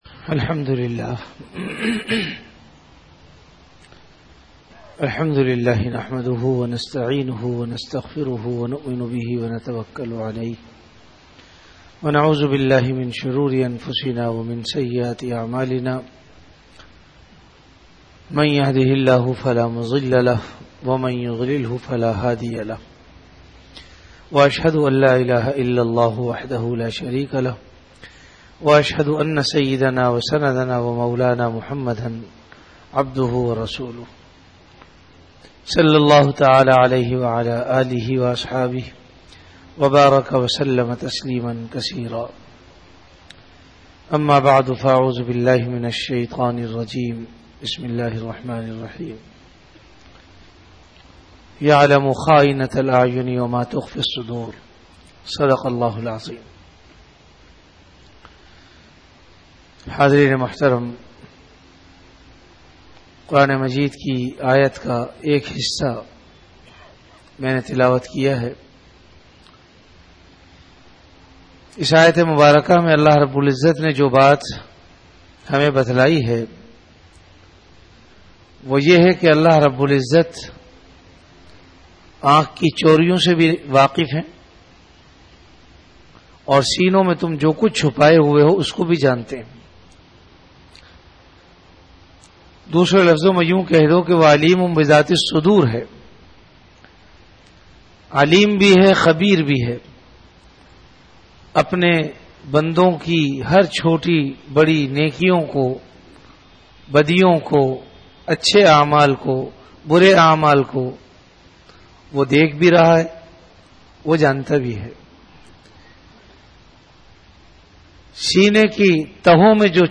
Bayanat · Jamia Masjid Bait-ul-Mukkaram, Karachi
Event / Time After Isha Prayer